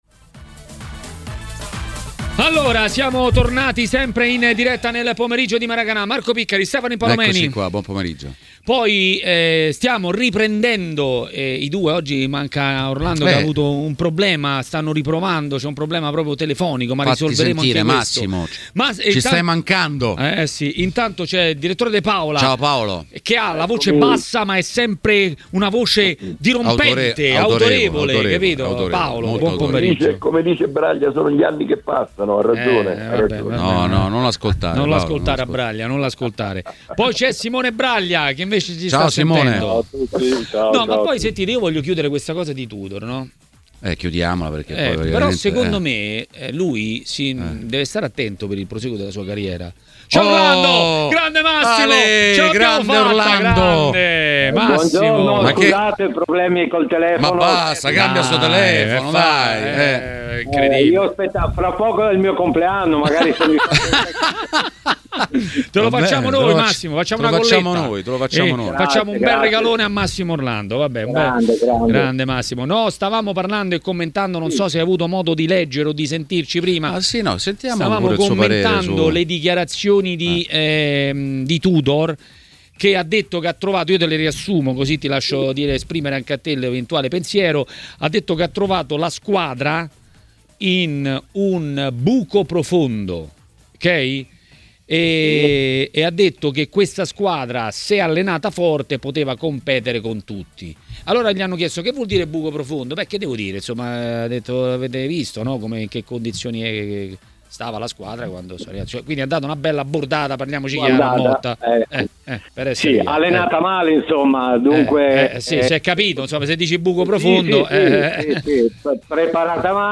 Le Interviste